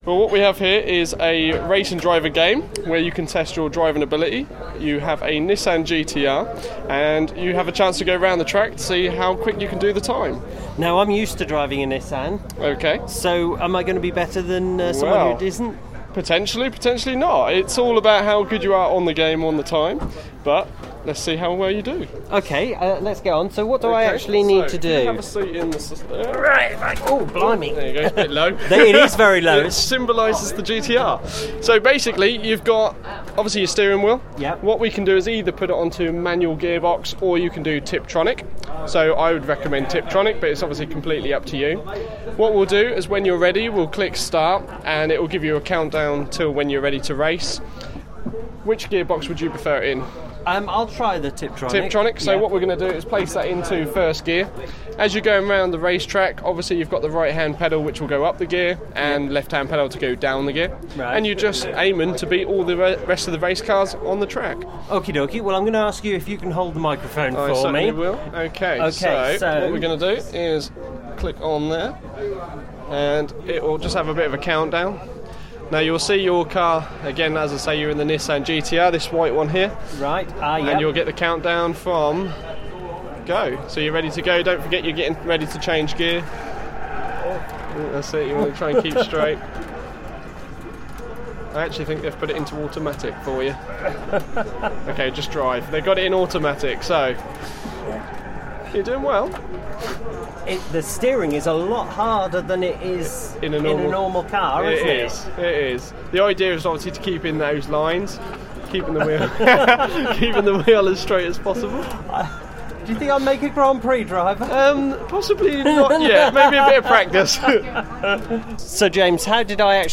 Well at the Aylsham Show people had a chance to drive Nissan’s touring car simulator.